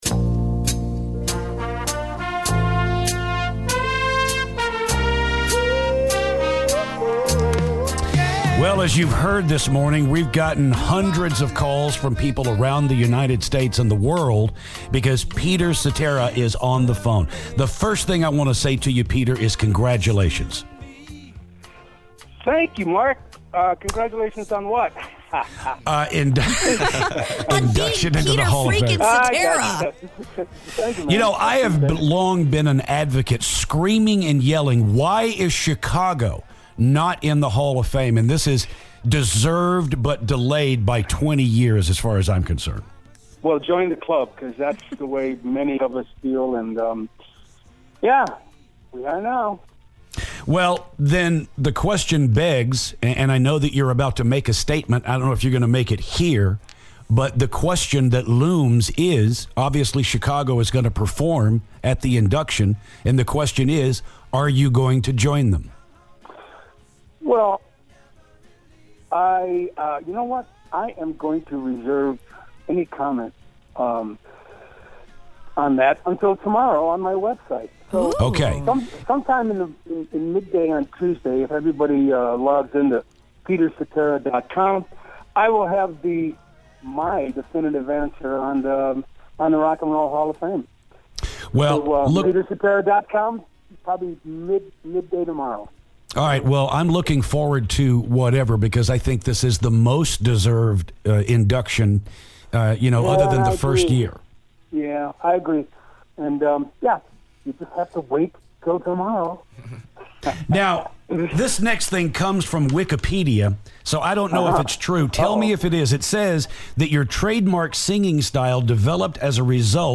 Former Chicago lead singer Peter Cetera calls the show!